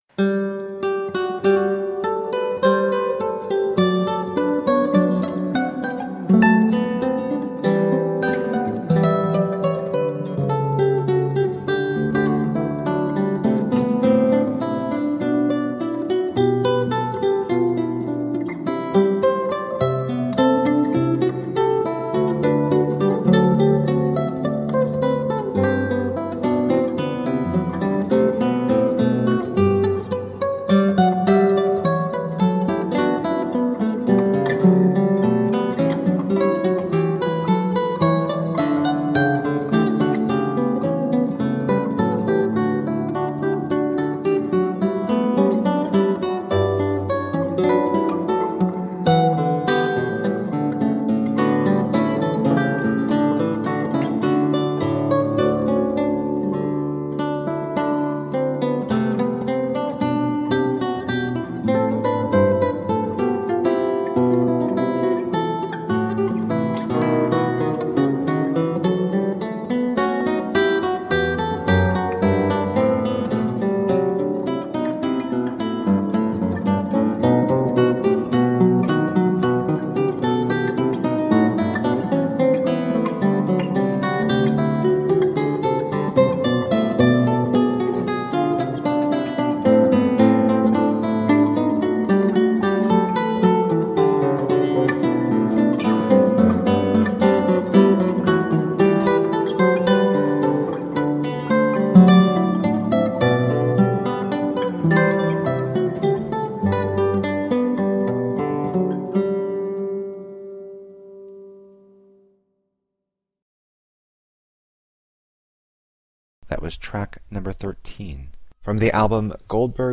Elegant classical guitar